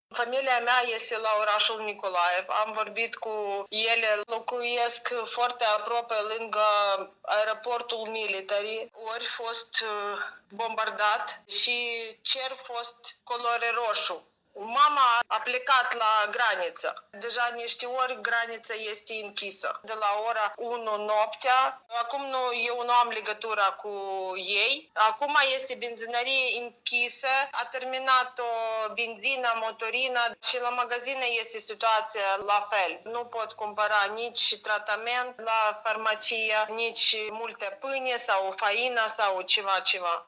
o studentă din Ucraina